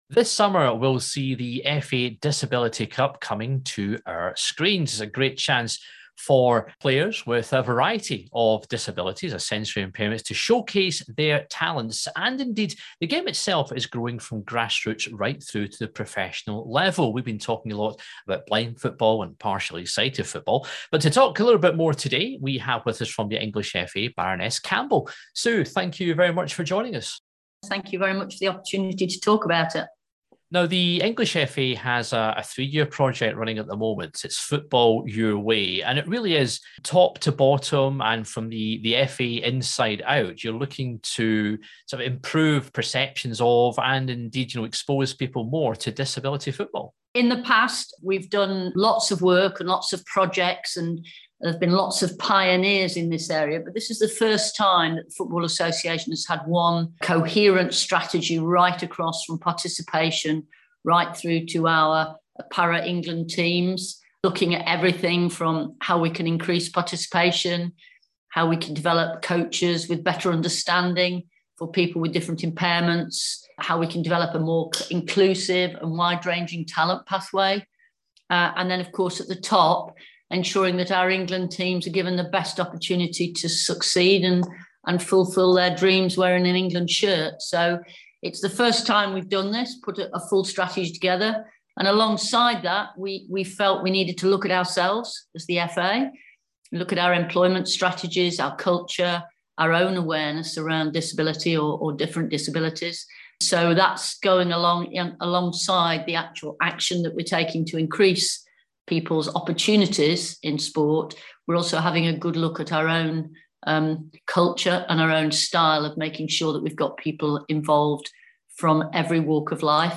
spoke to Baroness Sue Campbell from the FA to find out more.